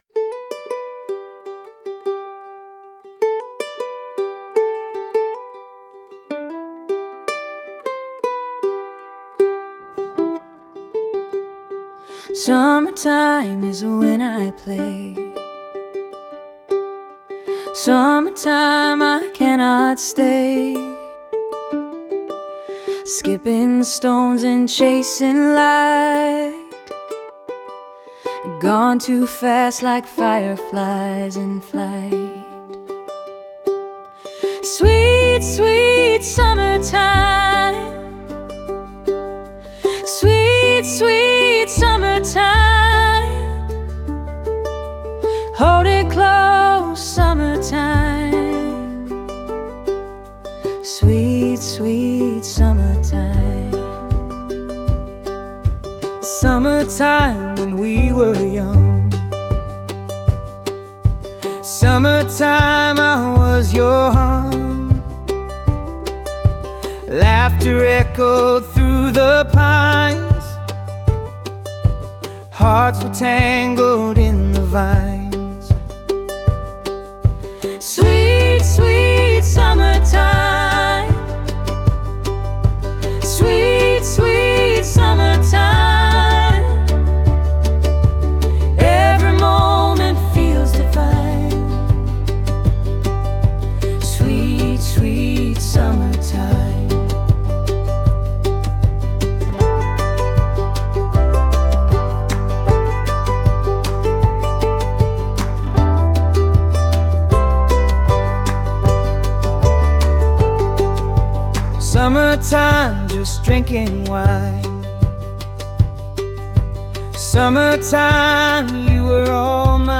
SummertimeUkulele.mp3